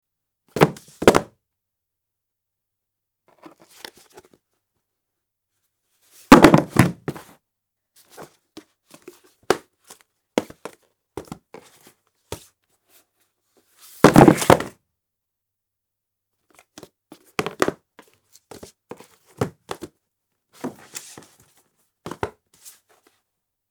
Sound：Book
ハードカバー本を積み重ねる音（単発）